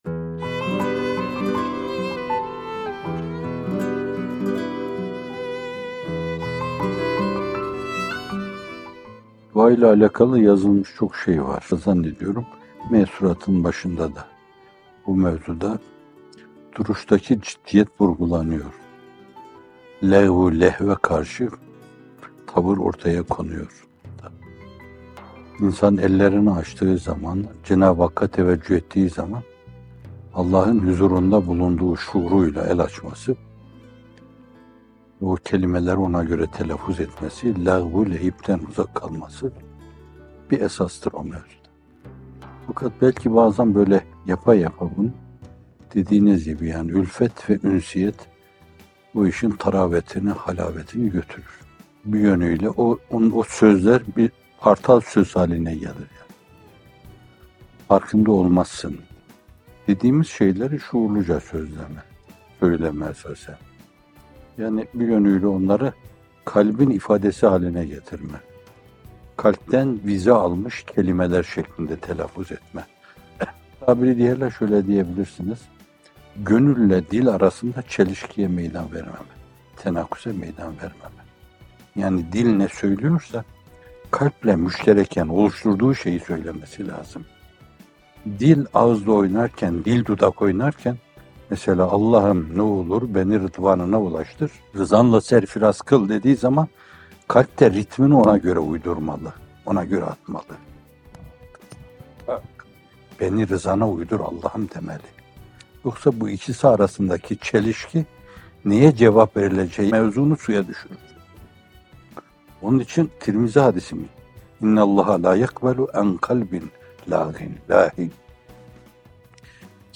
Bir Nefes (83) – Dua’da Gönül-Dil Uyumu - Fethullah Gülen Hocaefendi'nin Sohbetleri